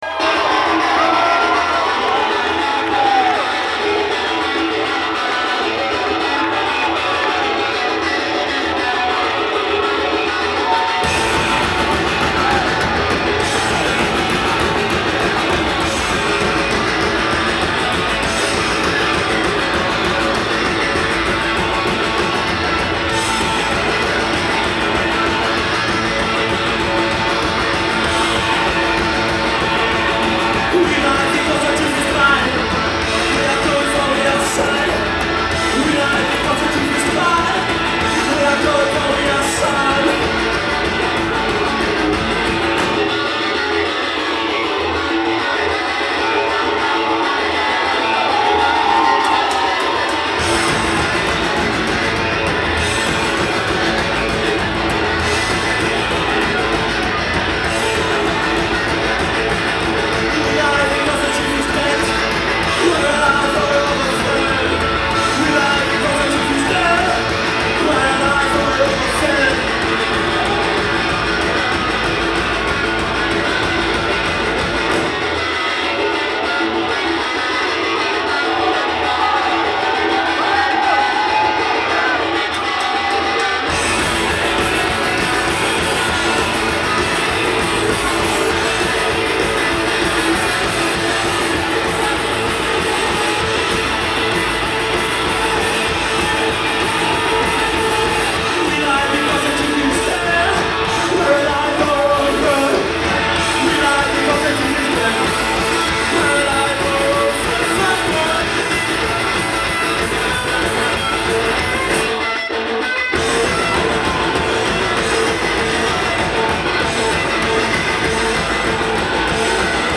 Live at The Paradise